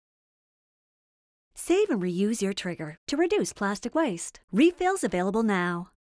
Publicité (Scrubbing Bubbles) - ANG